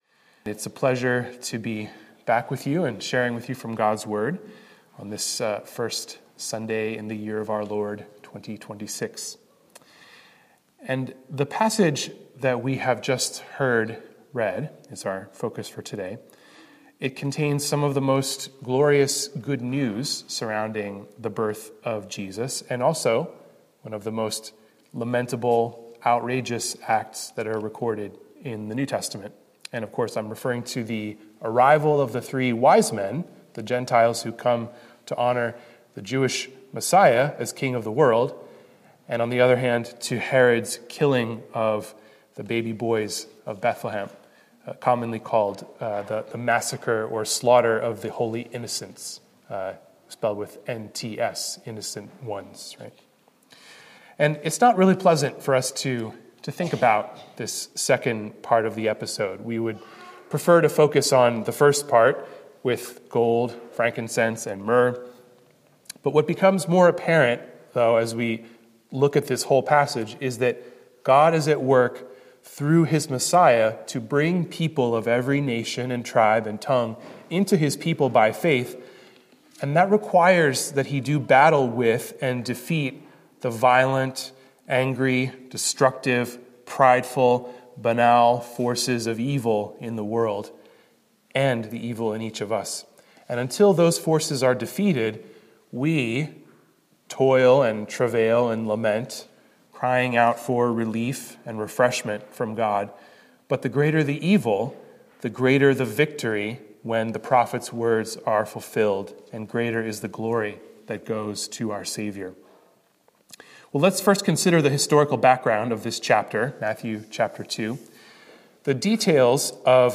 Sermon Scriptures: Matthew 2:1–23; Jeremiah 31:10–20.